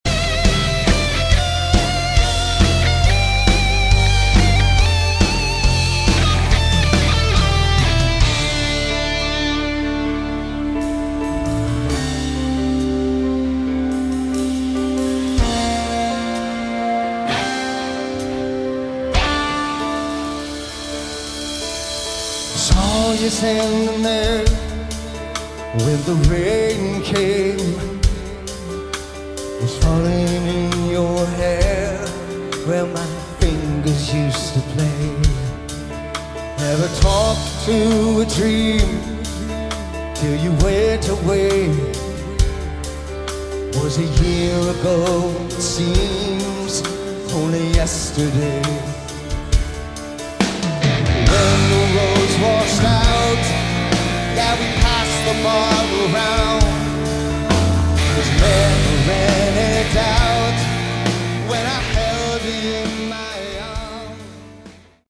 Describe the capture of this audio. So awesome to see this legendary aor band on stage.